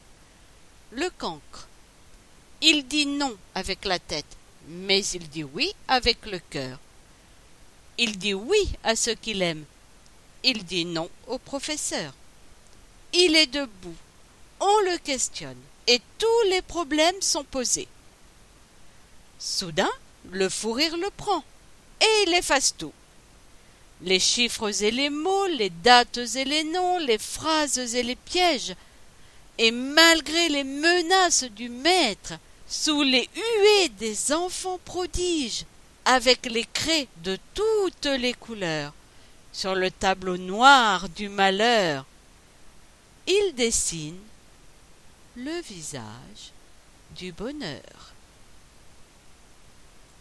Proposé par l'atelier théâtre adulte du Centre Nelson Mandela